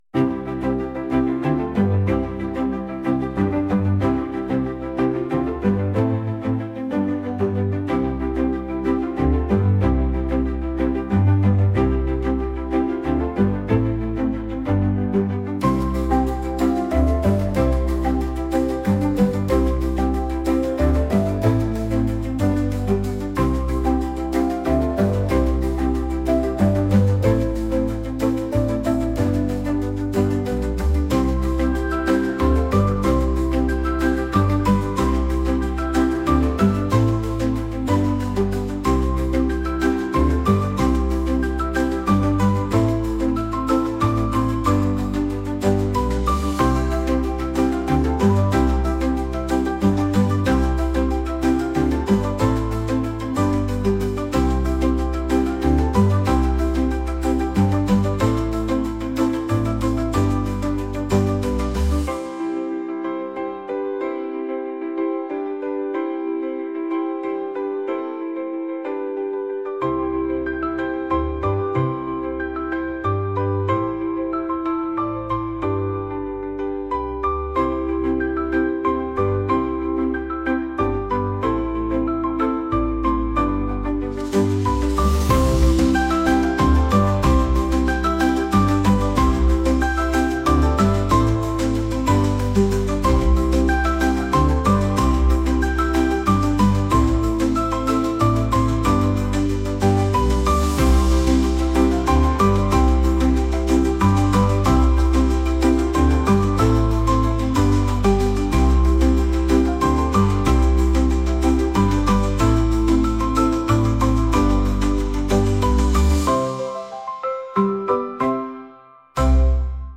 「おしゃれ」